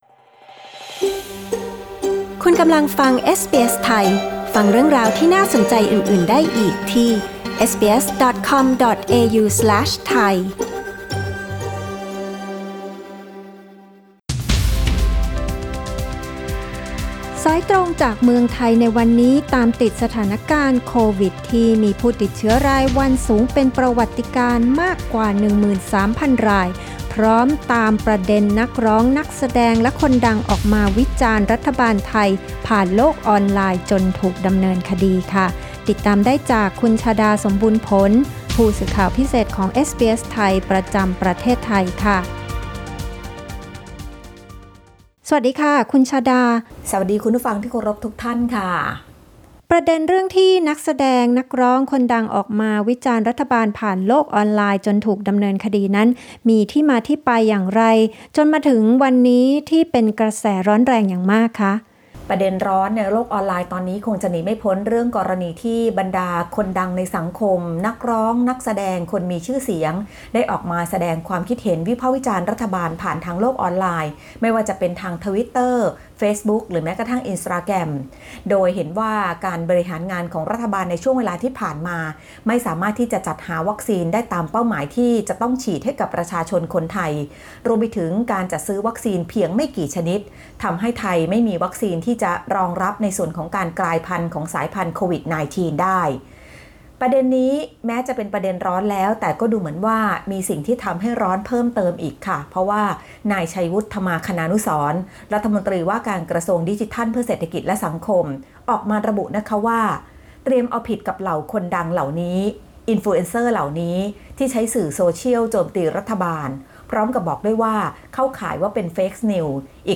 ติดตามสถานการณ์โควิด-19 ในช่วงรายงานข่าวสายตรงจากเมืองไทย ของเอสบีเอส ไทย Source: Pixabay